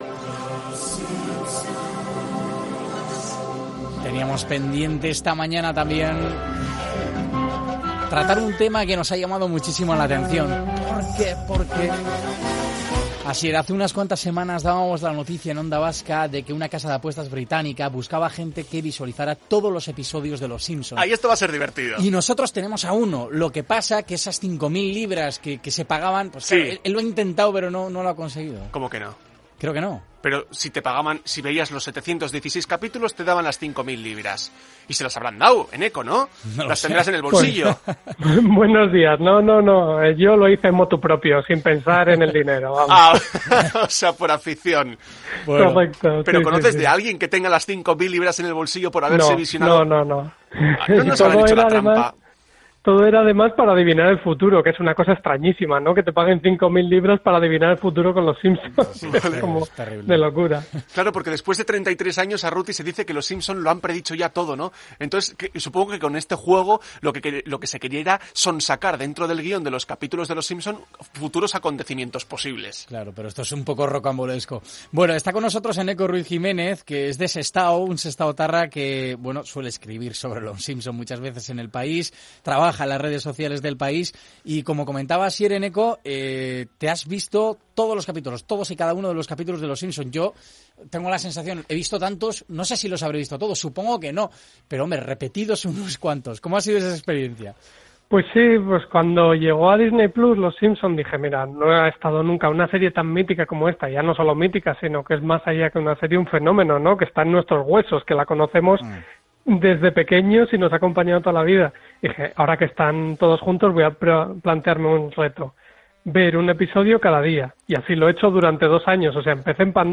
Información y entretenimiento para las mañanas del fin de semana